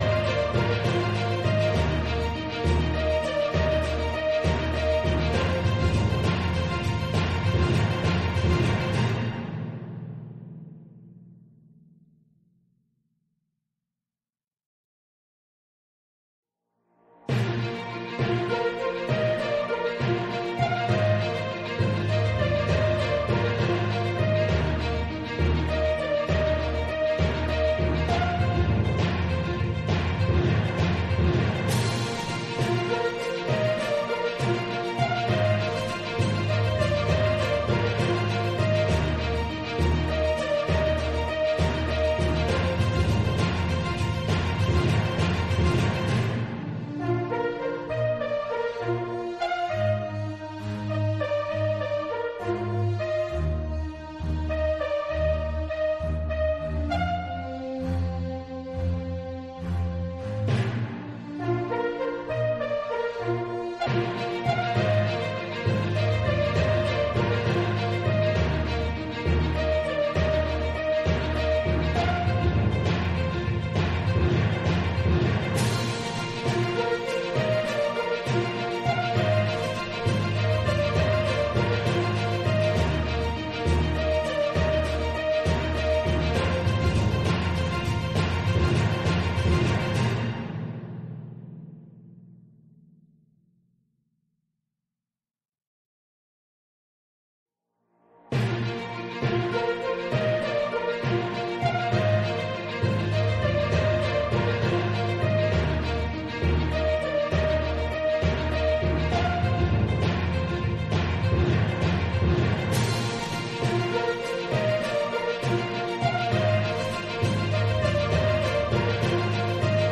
The show features discussions with freedom-loving Americans who are making a positive impact.